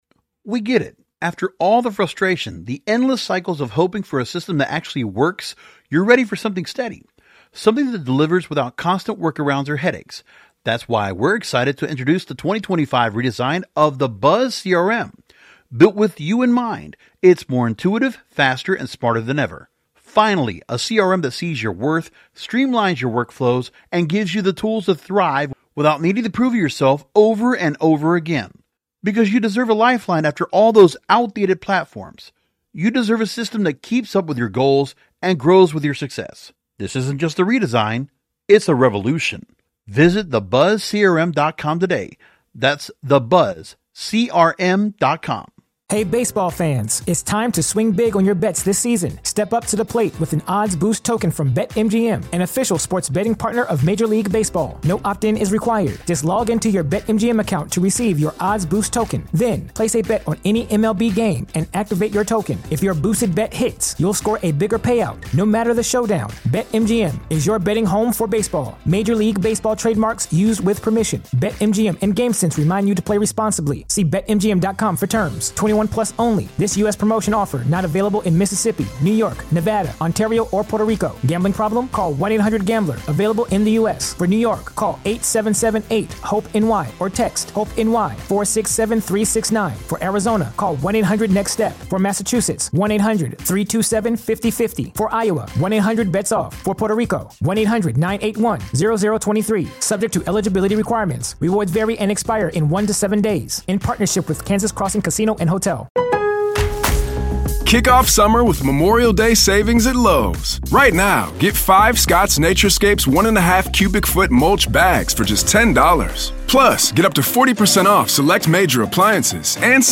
The interview emphasizes the importance of community, mindful consumption, and creating elevated experiences around cannabis.